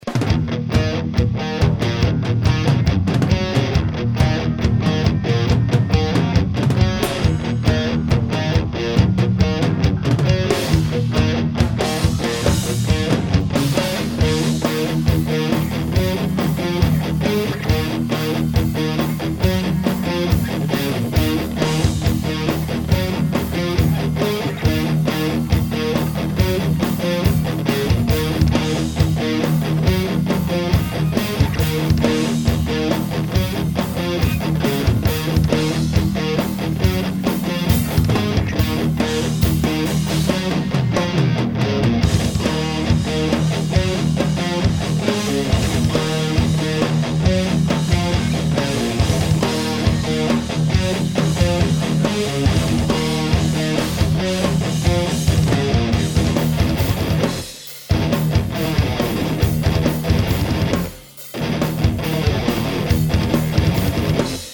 Pre vsetkych priaznivcov internetoveho mudrovania a pocuvania a porovnavania ukazok som pripravil 6 vzoriek kvazy toho isteho - 2 beglajtove gitary + basa + bicie. Rozdiel je iba v gitarach, boli pouzite viacere gitarove aparaty alebo modeling.